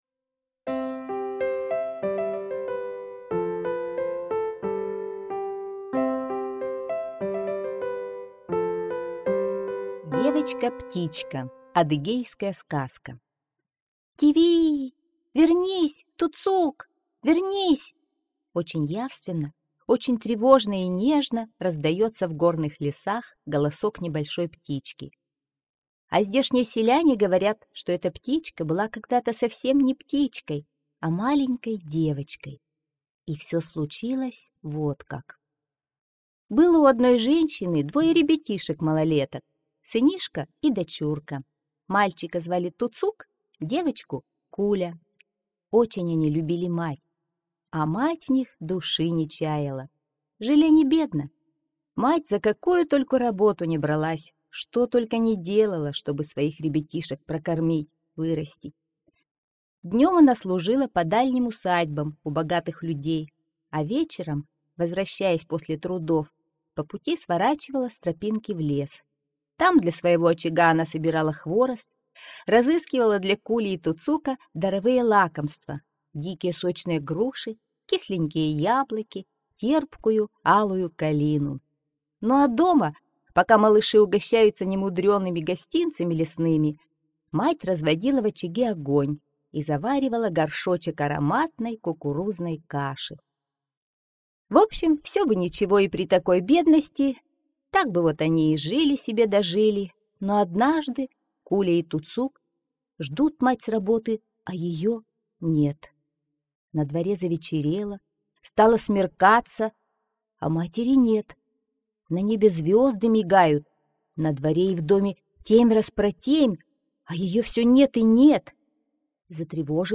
Девочка-птичка - адыгейская аудиосказка - слушать онлайн